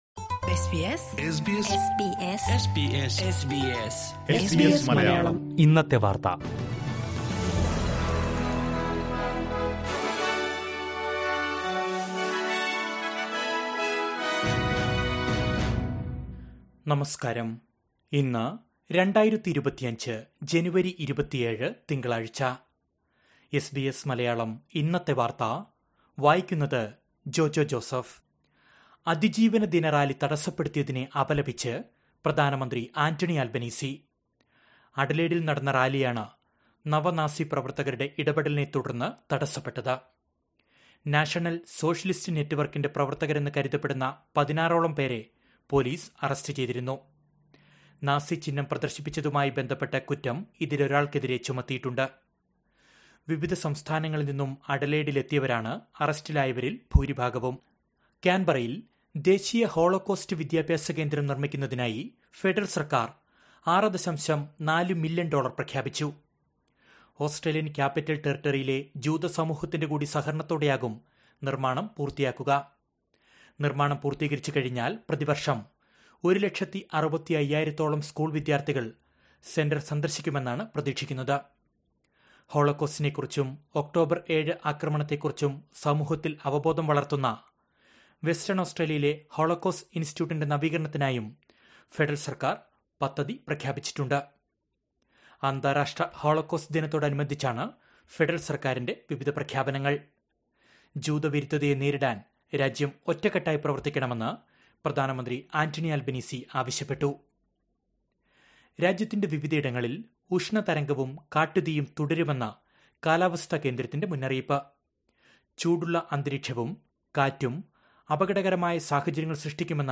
2025 ജനുവരി 27ലെ ഓസ്‌ട്രേലിയയിലെ ഏറ്റവും പ്രധാന വാര്‍ത്തകള്‍ കേള്‍ക്കാം...